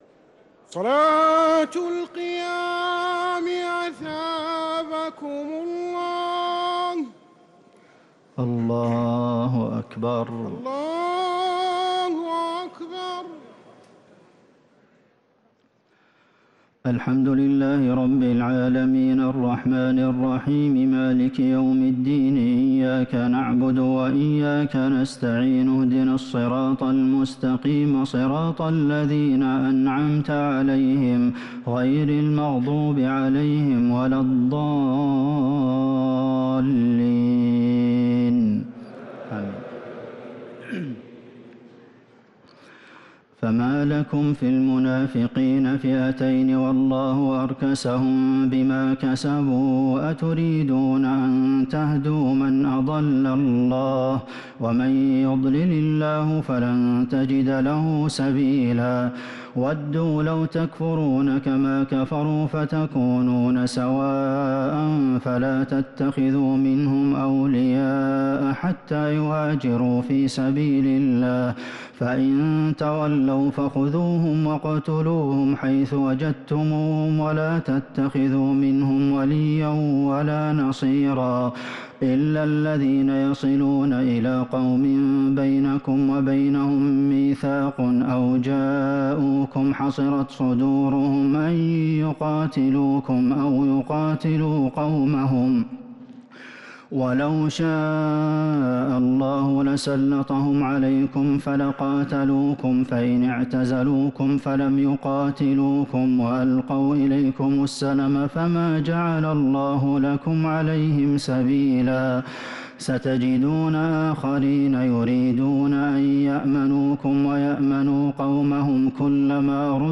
تراويح ليلة 7 رمضان 1447هـ من سورة النساء {88-134} Taraweeh 7th night Ramadan 1447H Surah An-Nisaa > تراويح الحرم النبوي عام 1447 🕌 > التراويح - تلاوات الحرمين